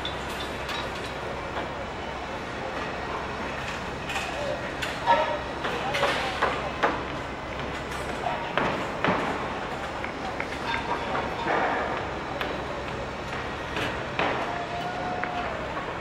construction.ogg